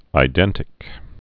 (ī-dĕntĭk)